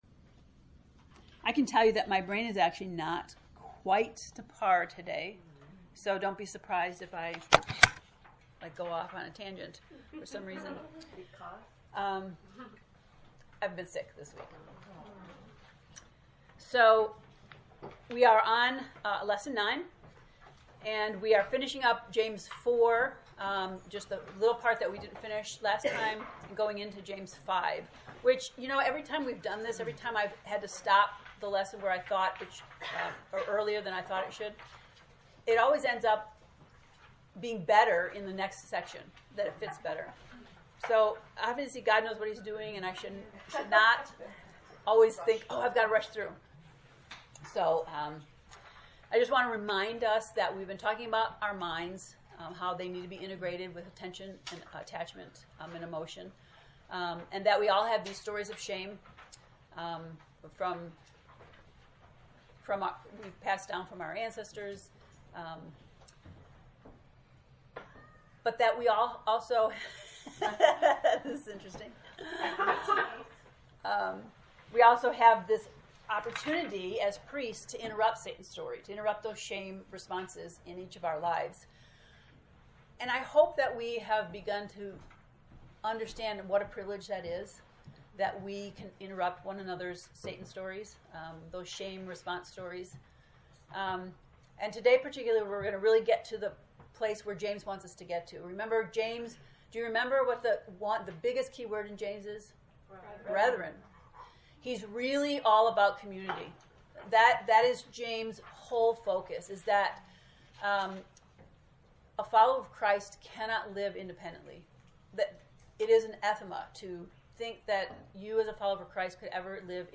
To listen to the lesson 9 lecture, “Priestly Resistors,” click below: